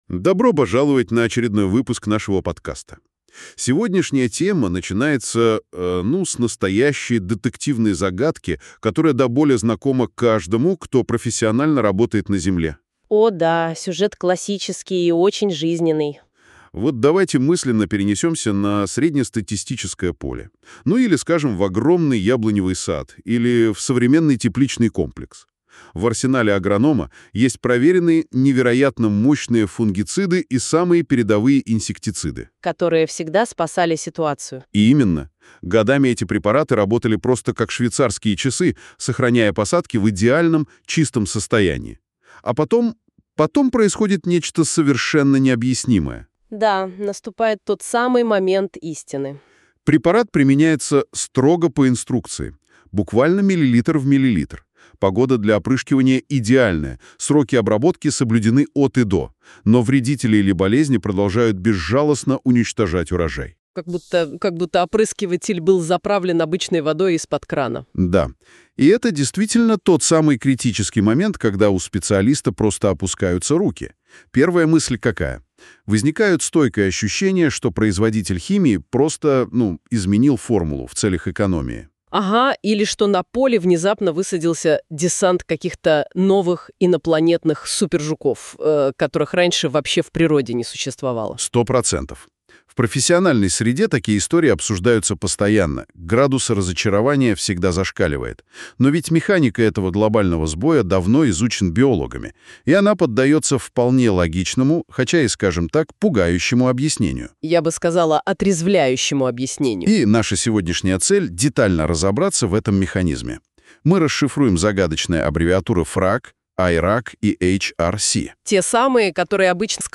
Вы также можете послушать аудиоверсию этого материала в виде подкаста: